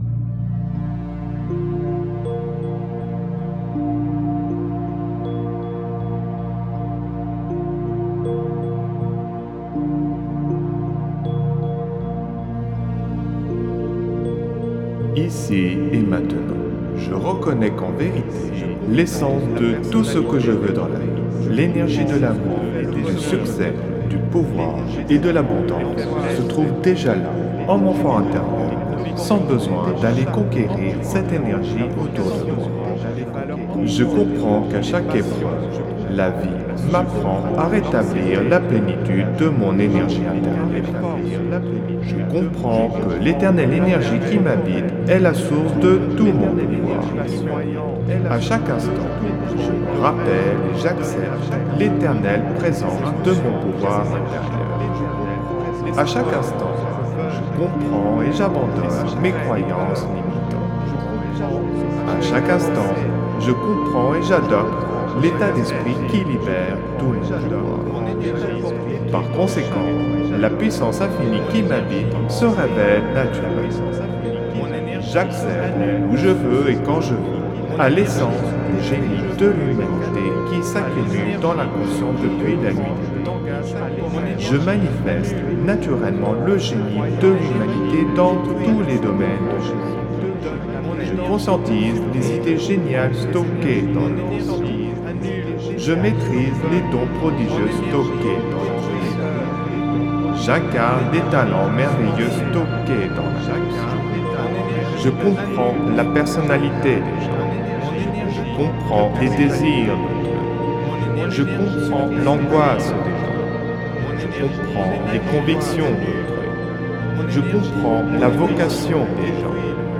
(Version ÉCHO-GUIDÉE)
Alliage ingénieux de sons et fréquences curatives, très bénéfiques pour le cerveau.
Pures ondes gamma intenses 68,46 Hz de qualité supérieure. Puissant effet 3D subliminal écho-guidé.